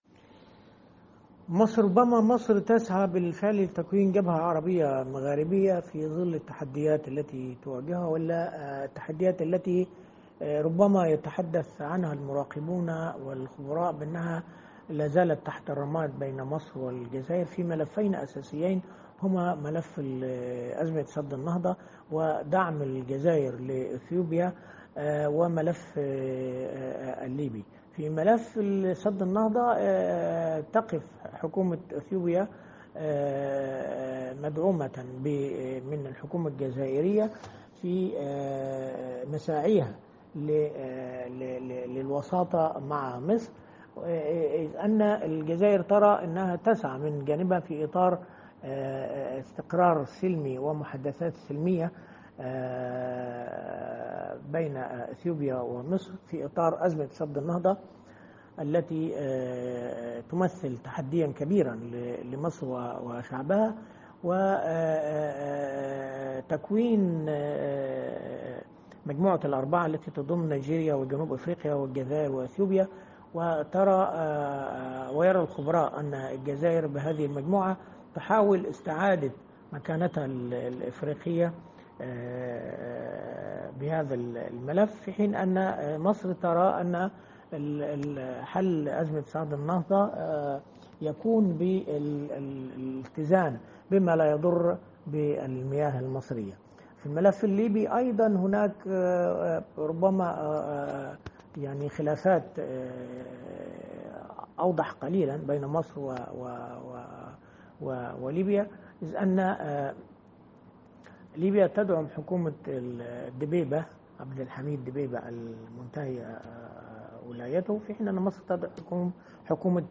الكاتب الصحفي والمحلل السياسي